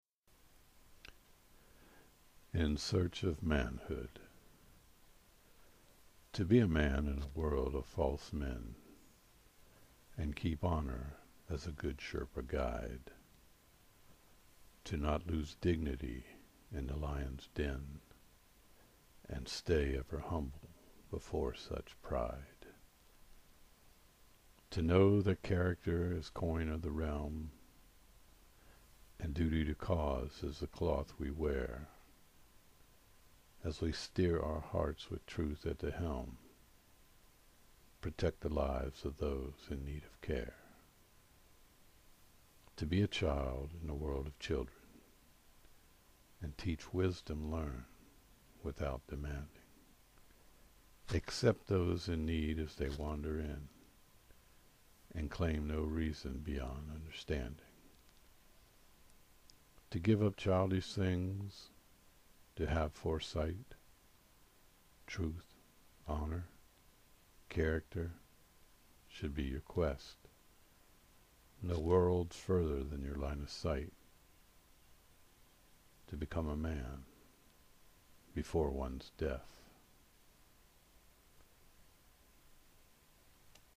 The way you read it, you sounded like such a GOOD and wise man!!!